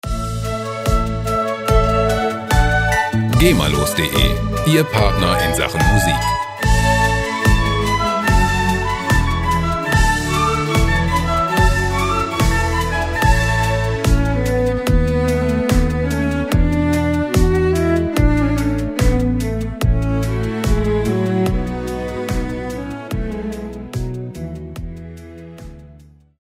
Gema-freie Klassik Loops
Musikstil: Baroque Pop
Tempo: 73 bpm